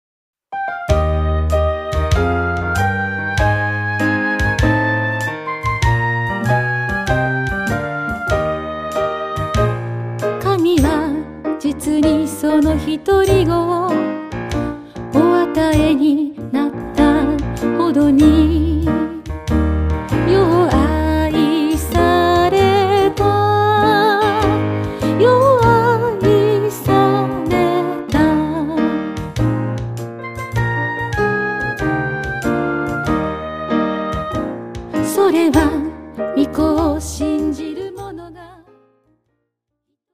大変有名な聖書の一節、ヨハネの福音書３．１６を明るく楽しい楽曲に乗せて賛美しました。